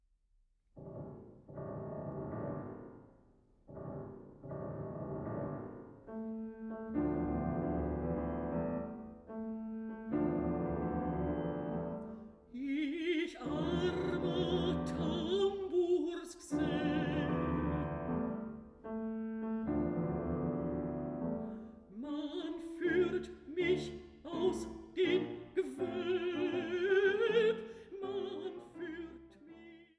Mezzosopran
Klavier